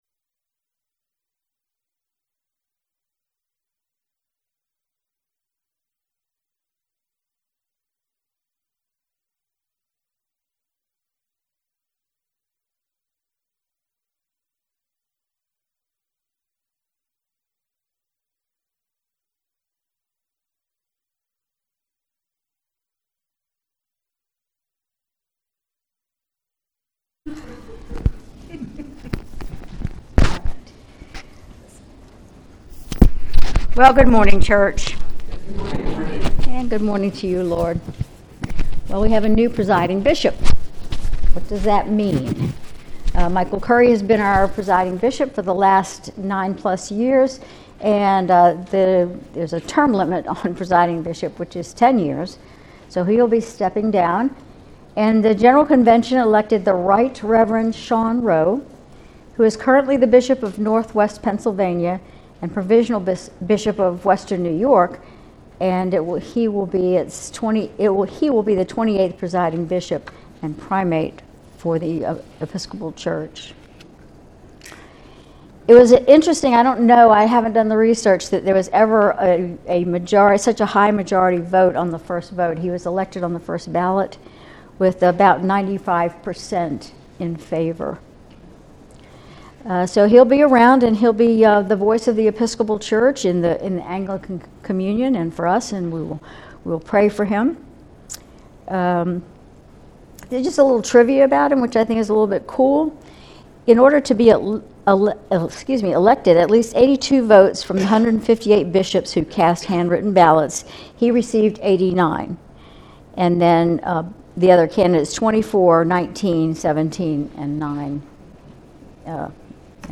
Sermon June 30, 2024